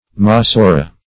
Masora \Ma*so"ra\, n. [NHeb. m[=a]s[=o]r[=a]h tradition.]